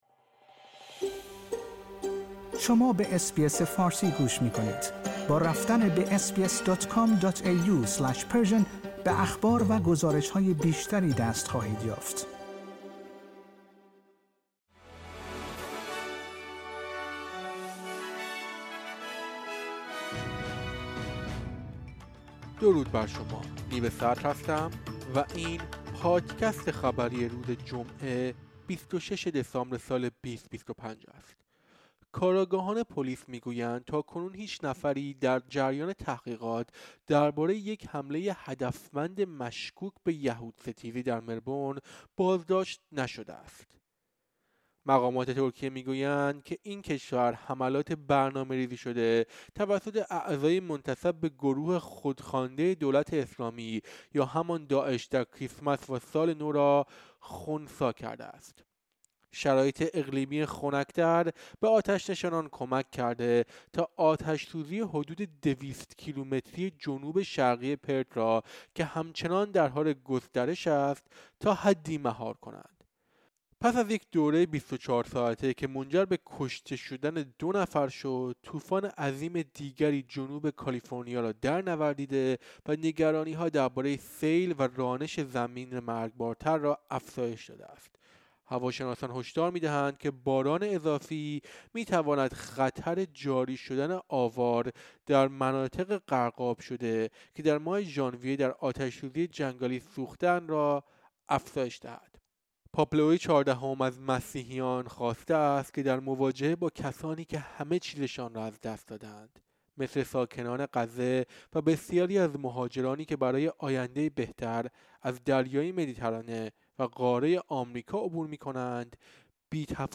در این پادکست خبری مهمترین اخبار روز جمعه ۲۶ دسامبر ارائه شده است.